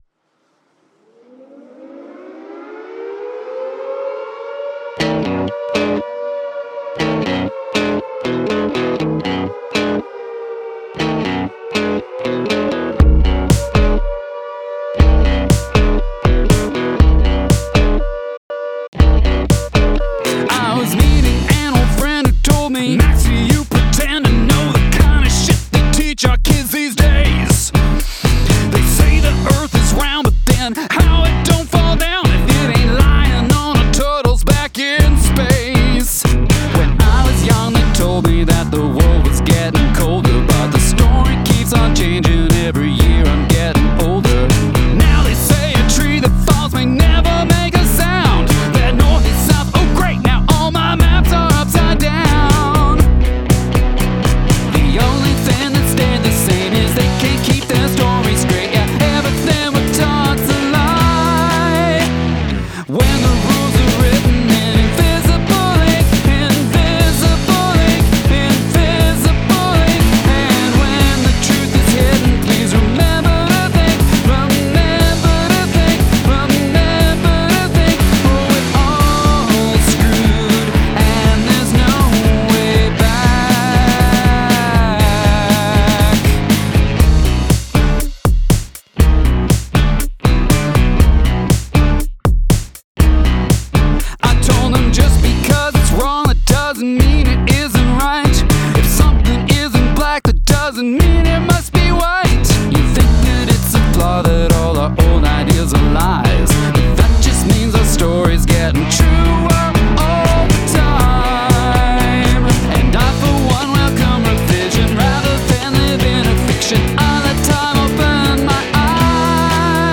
I’m digging the groove, especially on the chorus.
Your vocal is pretty darn good.